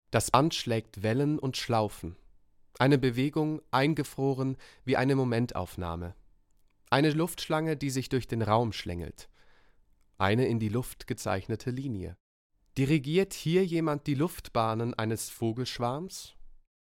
Audioguide zur Ausstellung im Kunstverein Aalen